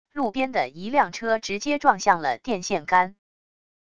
路边的一辆车直接撞向了电线杆wav音频